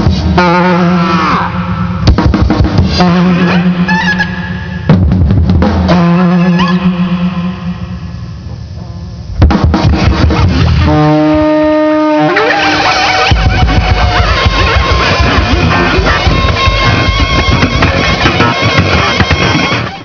High-risk, high-impact improvisation